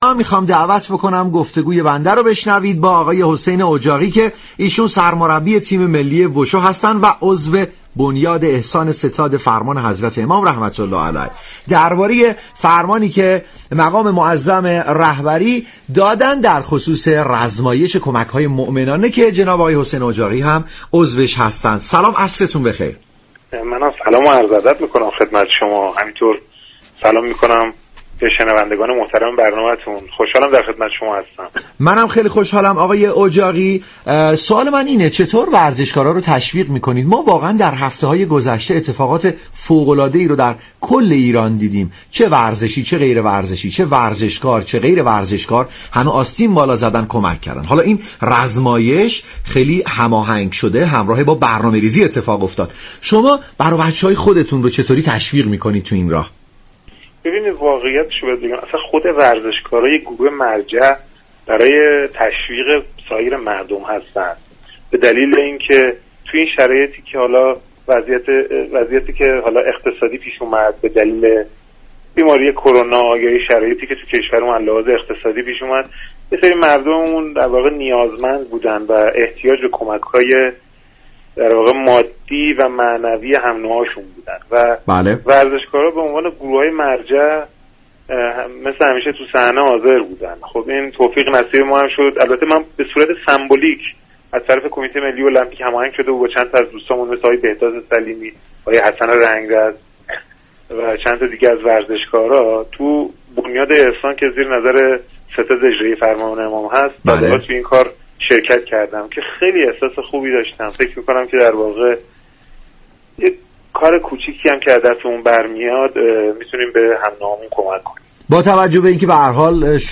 در خصوص رزمایش ورزشكاران همدل به گفتگو با برنامه عصرانه رادیو ورزش پرداخت.